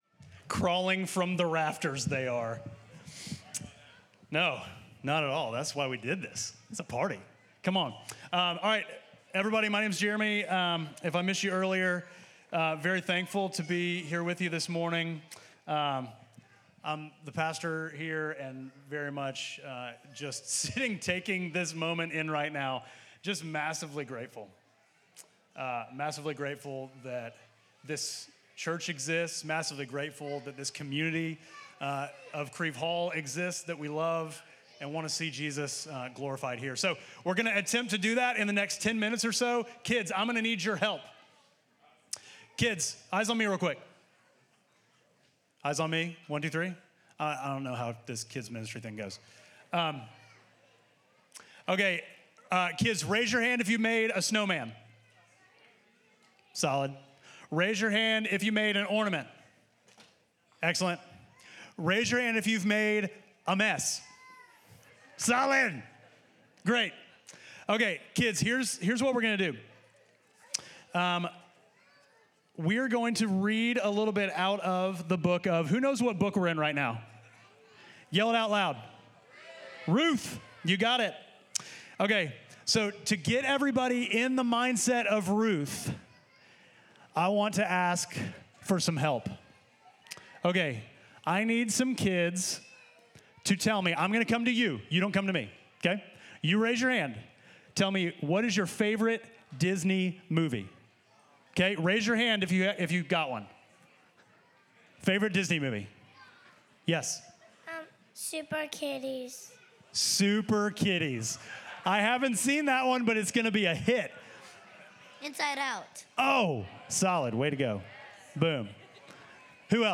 Midtown Fellowship Crieve Hall Sermons Peace in the Badlands Dec 08 2024 | 00:23:44 1x 00:00 / 00:23:44 Subscribe Share Apple Podcasts Spotify Overcast RSS Feed Share Link Embed